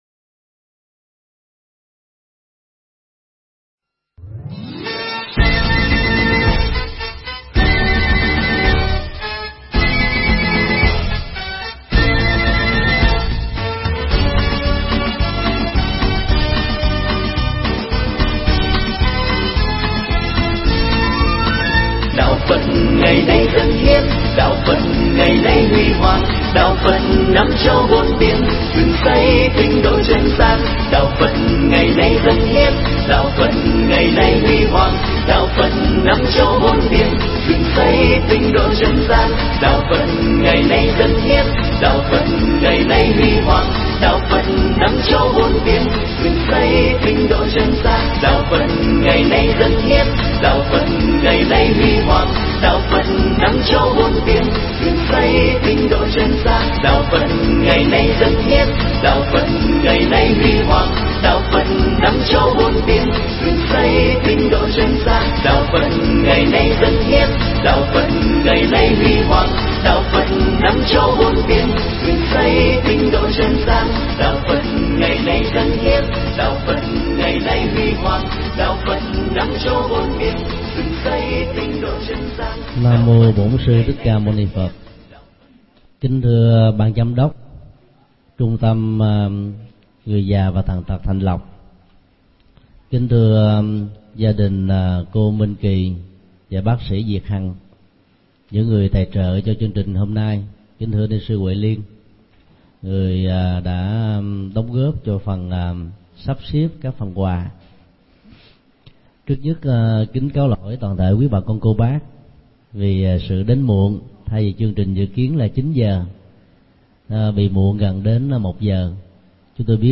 Pháp âm Chuyển họa thành phúc do thầy Thích Nhật Từ giảng tại Trung Tâm Nuôi Dưỡng Người Già Và Tàn Tật Thạnh Lộc – Q.12, ngày 26 tháng 12 năm 2008.